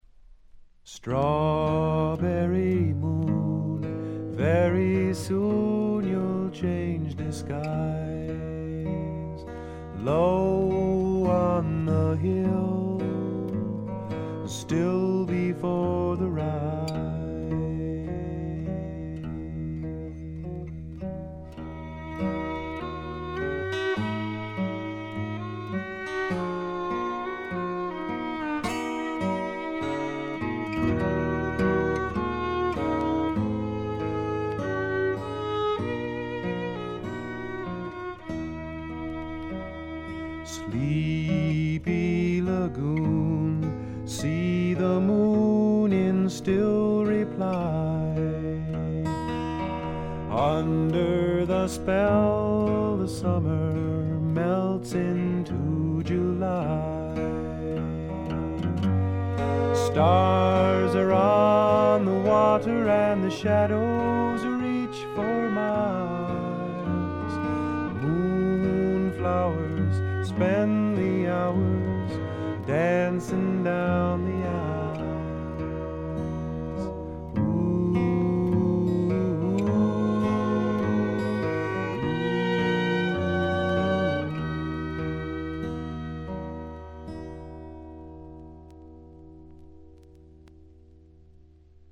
ほとんどノイズ感無し。
全体に静謐で、ジャケットのようにほの暗いモノクロームな世界。
試聴曲は現品からの取り込み音源です。
Vocals, Guitars, Harmonica